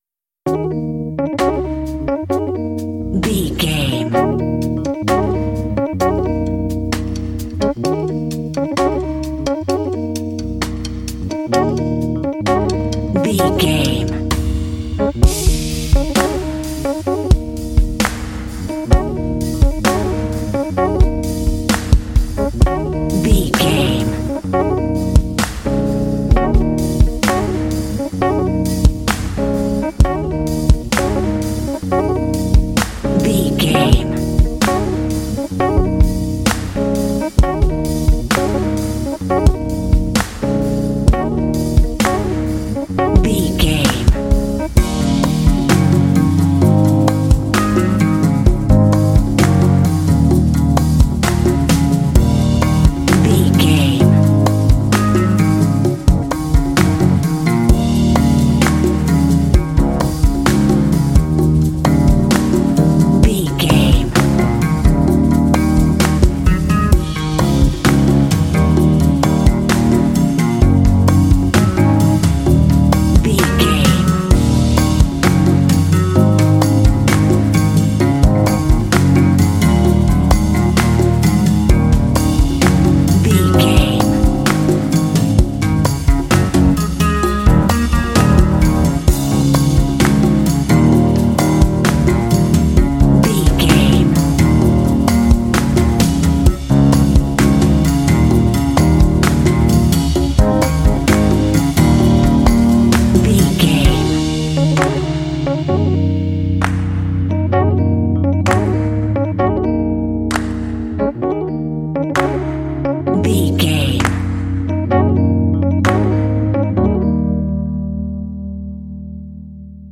Aeolian/Minor
E♭
Slow
cool
funky
electric organ
bass guitar
drums
hip hop